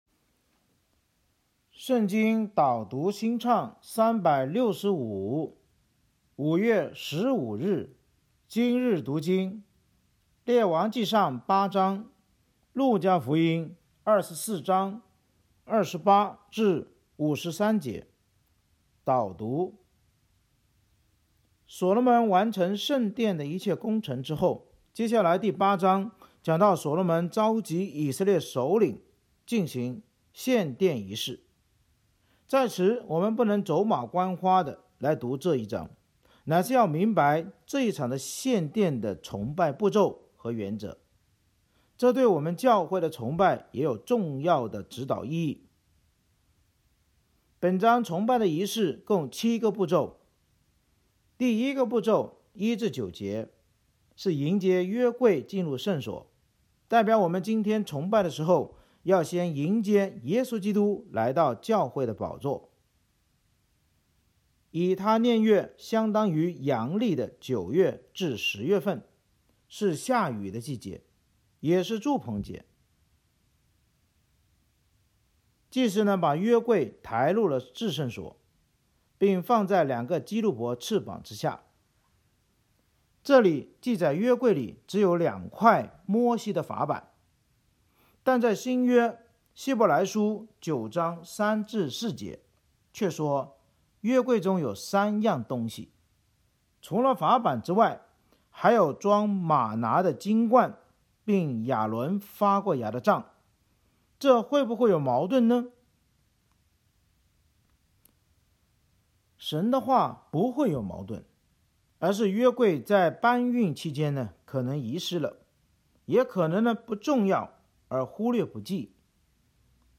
圣经导读&经文朗读 – 05月15日（音频+文字+新歌）